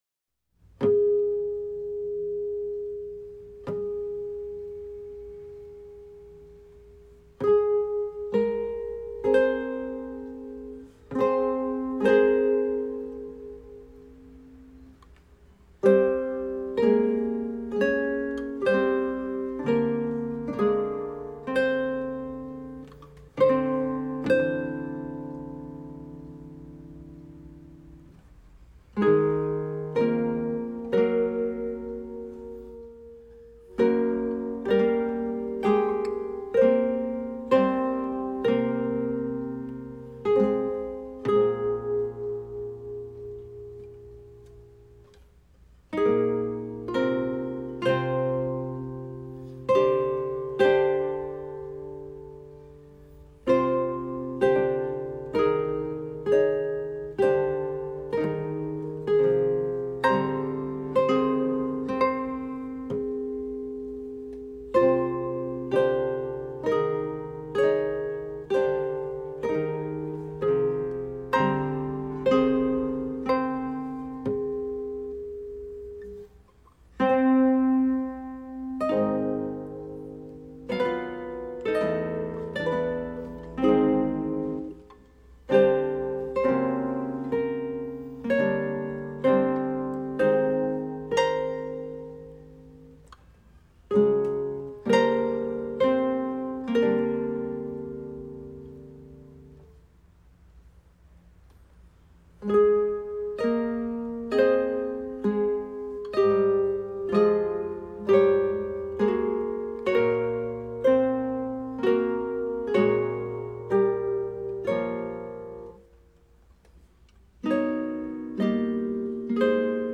for guitar quartet.
Violões